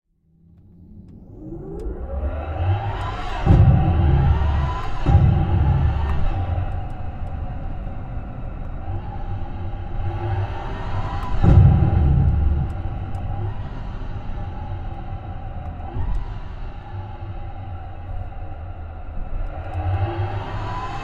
Écoutez le doux son de la performance
Appuyez doucement sur l’accélérateur, instantanément le volume sonore monte !
Hyundai_IONIQ_5_N_Sound_Supersonic.mp3